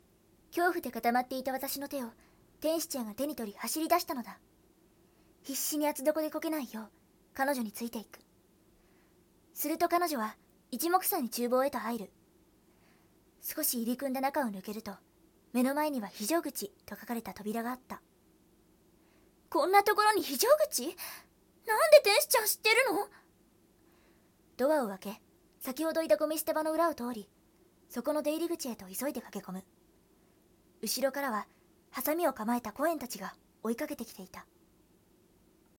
【💭🎀】台本37 ナレ② nanaRepeat